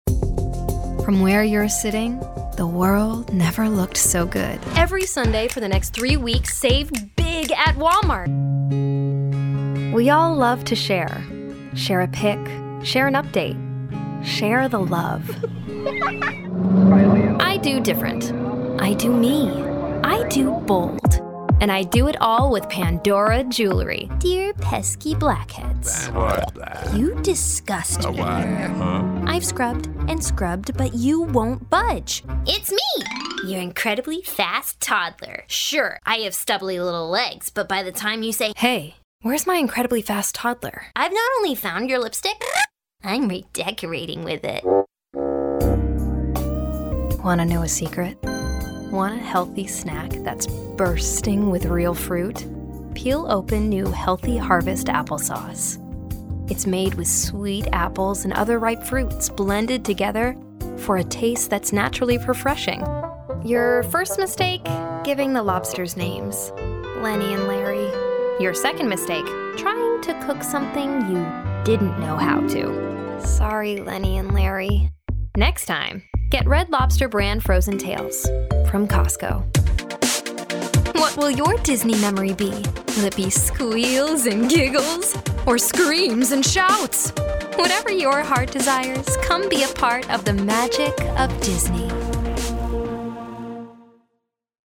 Female Voice Over, Dan Wachs Talent Agency.
Warm, Genuine, Conversational.
Commercial